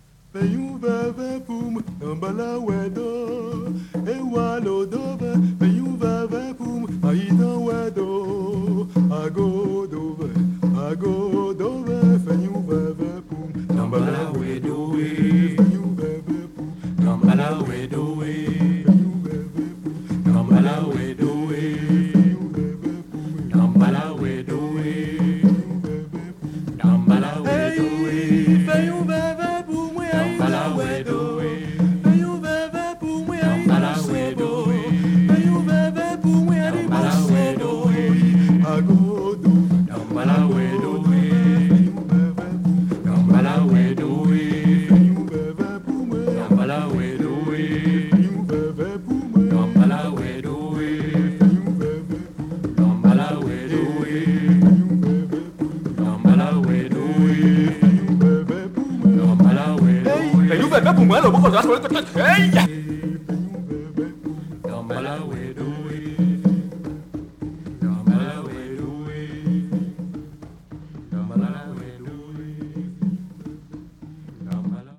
カリブ海の南国っぽさやアフロ的な土着的な風合いが非常にイイですね！